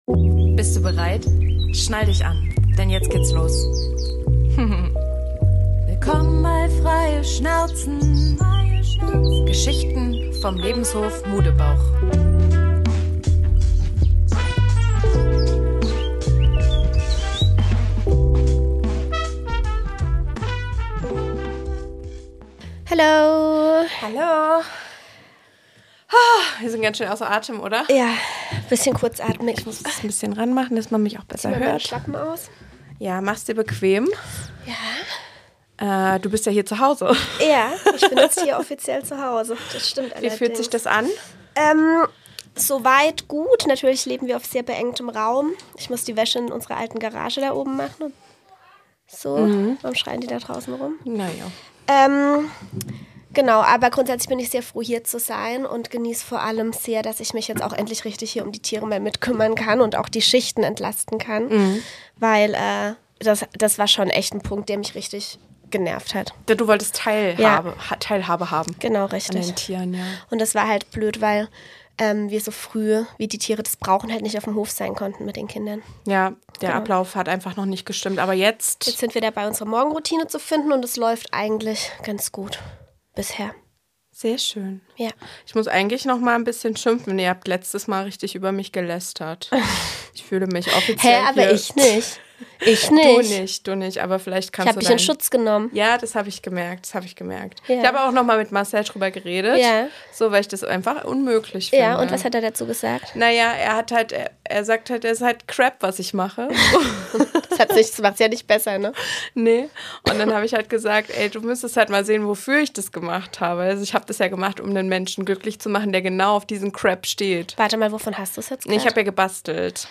besprechen bei einem gemütlichen Sit-in diese Fragen und noch viele mehr